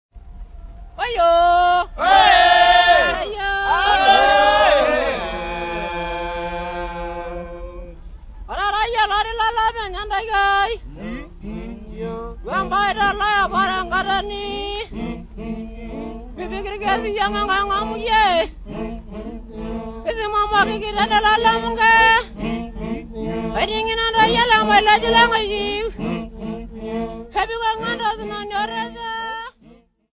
Arusha men
Folk Music
Field recordings
sound recording-musical
Indigenous music